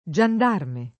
gendarme [Jend#rme] s. m. — antiq. giandarme [